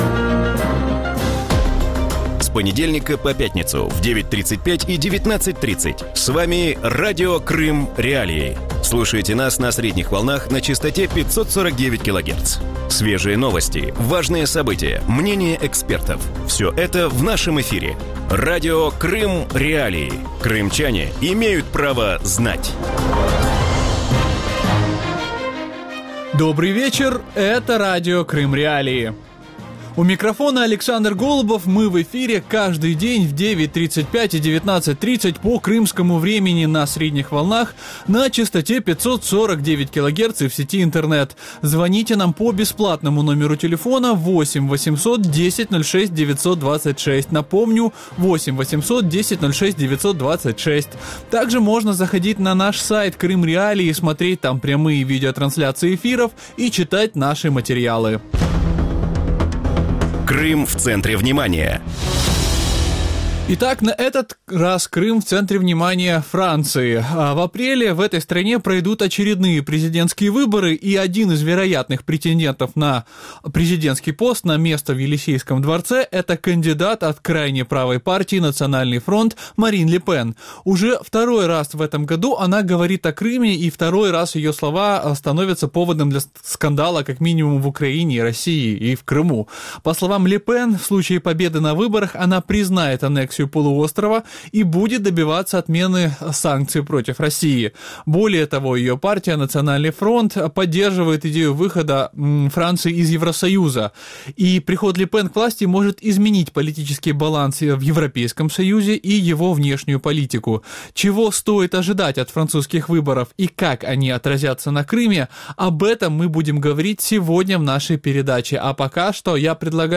Можлива перемога кандидата в президенти Франції від правоконсервативної партії «Національний фронт» Марін Ле Пен розхитає відносини Євросоюзу зі США, відсунувши проблему анексії Криму на другий план. Про це у вечірньому ефірі Радіо Крим.Реалії розповів аналітик українського Інституту...